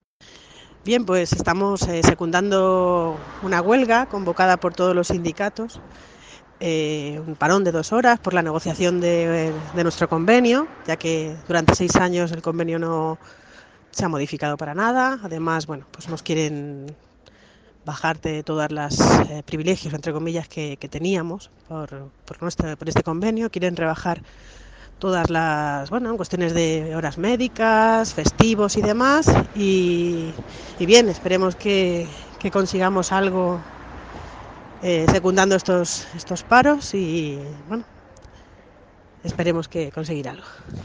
Opinión de una trabajadora
trabajadora_de_telemarketing.mp3